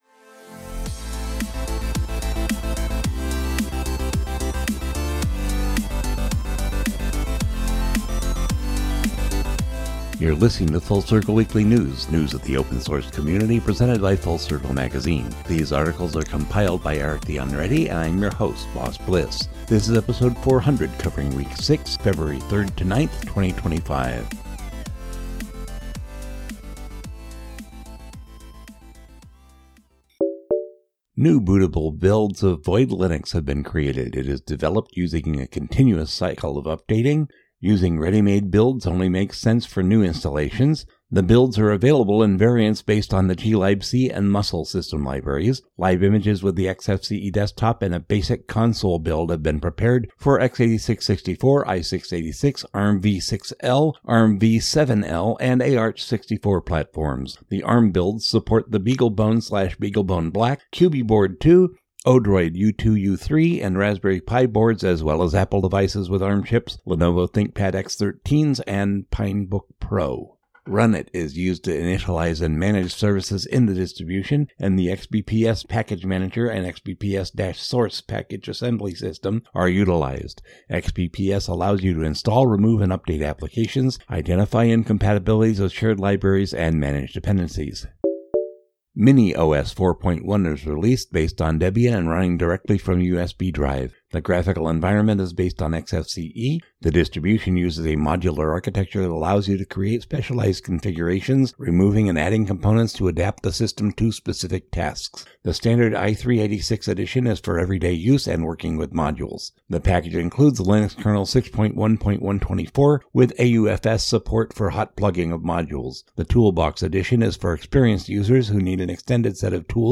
A short weekly podcast giving just the news, no chit-chat, no banter, just FOSS/Ubuntu/Linux news.